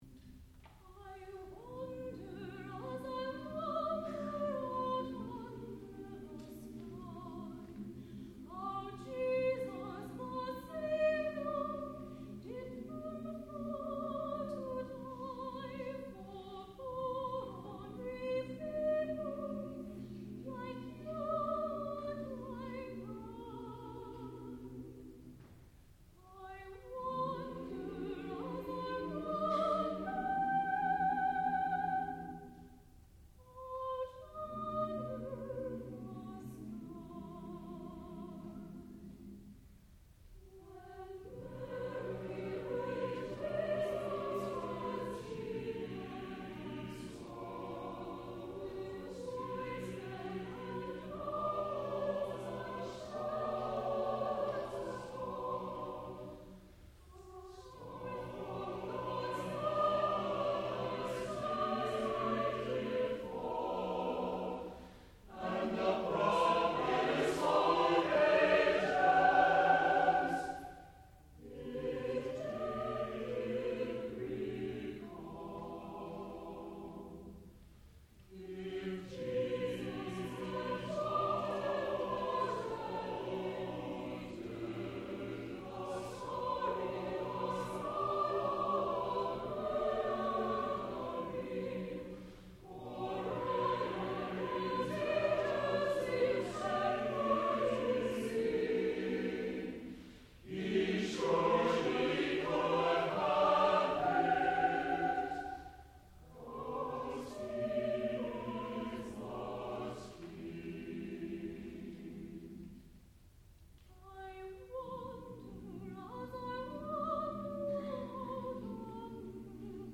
sound recording-musical
classical music
tenor